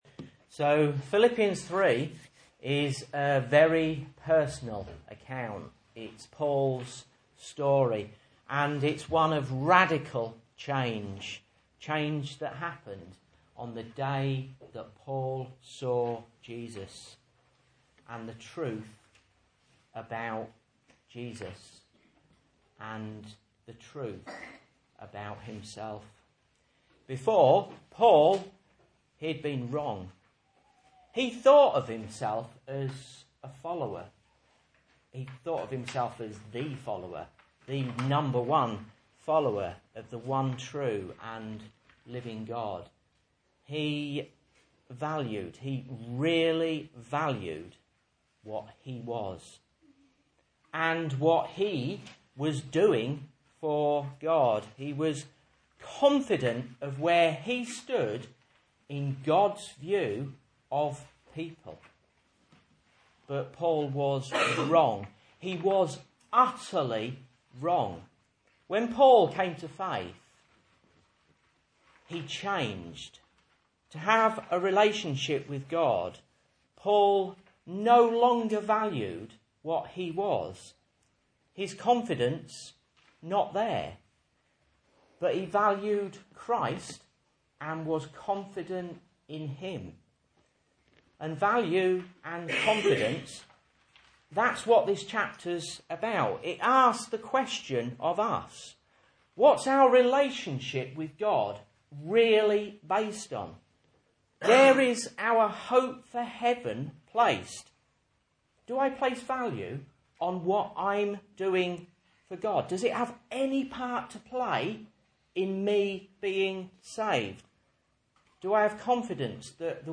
Message Scripture: Philippians 3:1-9 | Listen